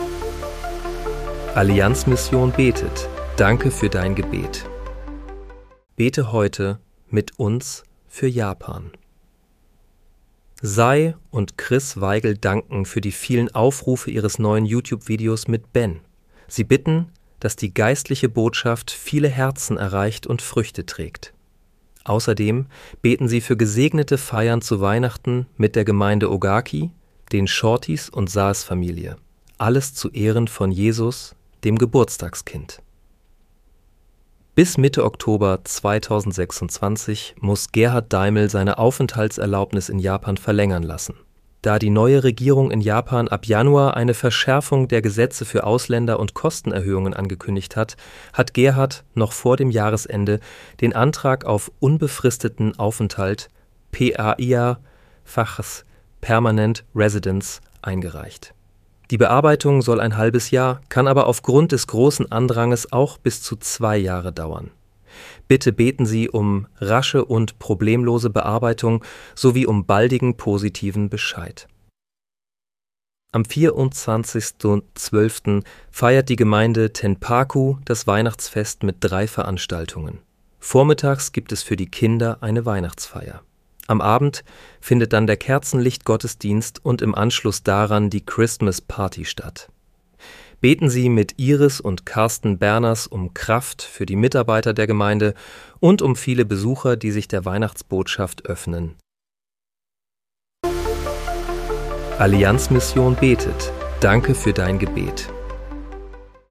Bete am 23. Dezember 2025 mit uns für Japan. (KI-generiert mit der